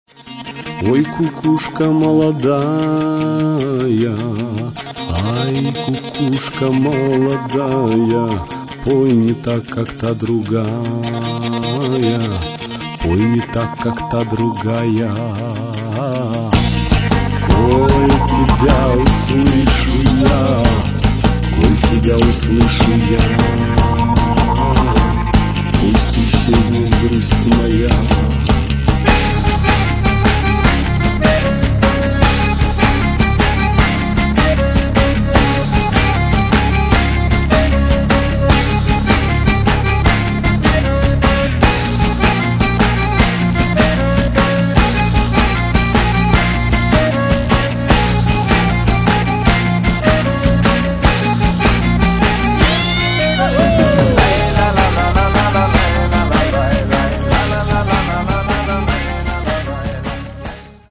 Catalogue -> Rock & Alternative -> Energy Rock